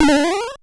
M1_HEN_JUMP.wav